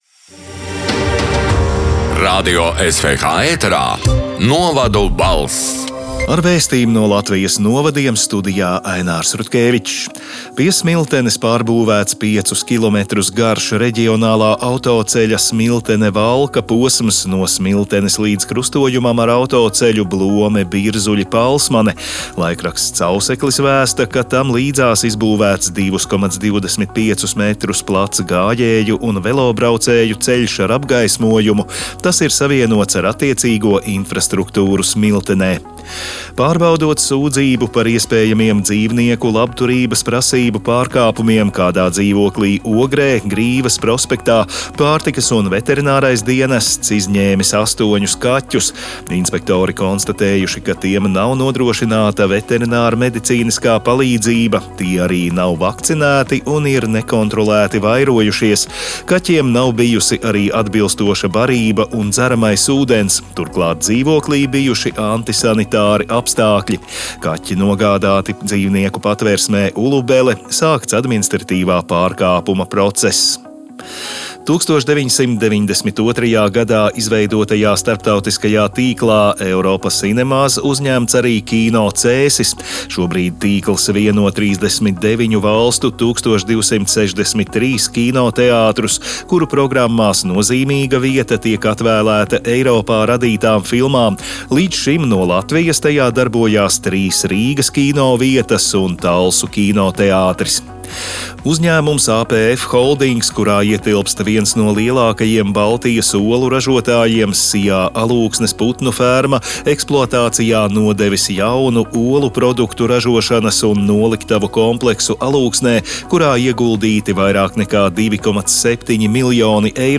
Radio SWH ēterā divreiz nedēļā izskan ziņu raidījums “Novadu balss”, kurā iekļautas Latvijas reģionālo mediju sagatavotās ziņas. Raidījumā Radio SWH ziņu dienests apkopo aktuālāko no laikrakstiem “Auseklis”, “Kurzemes Vārds”, “Zemgales Ziņas” un ”Vietējā Latgales Avīze”.
“Novadu balss” 20. novembra ziņu raidījuma ieraksts: